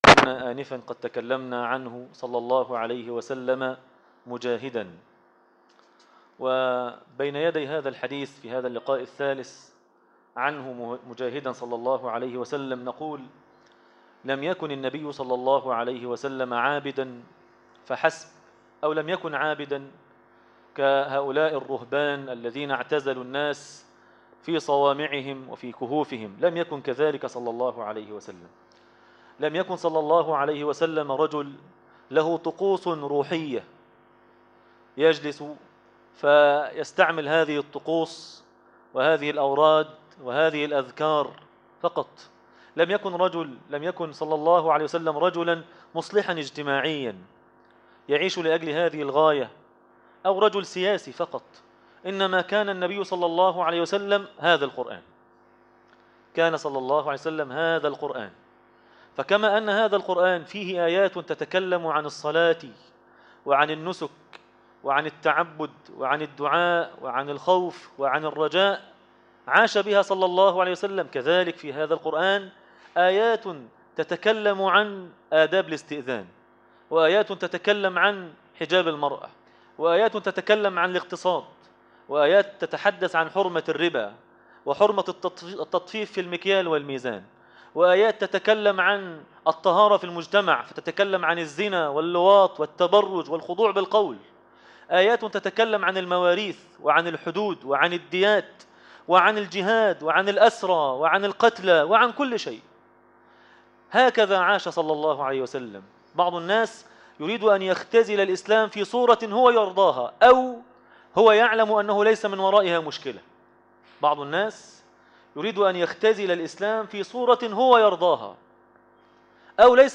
درس التراويح